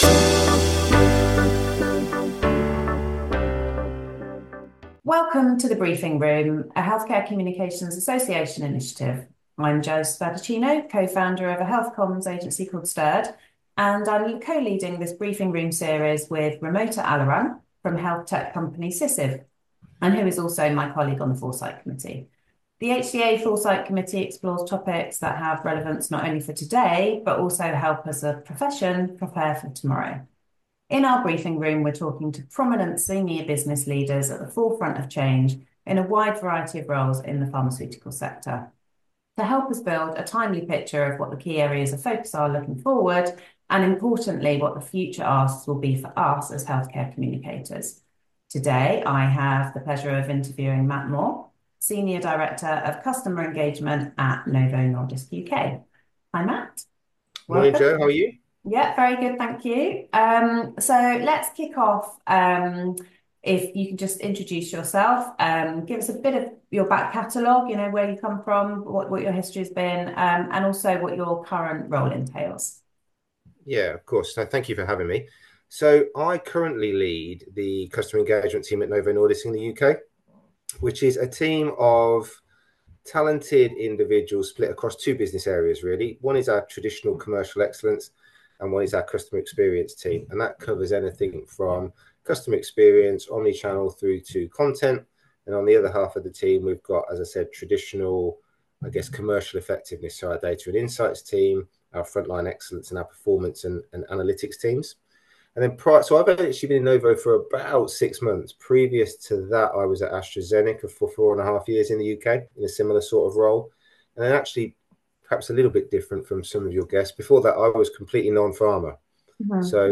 Brought to you by the HCA Foresight Committee, this interview series explores key industry challenges and opportunities with top healthcare leaders.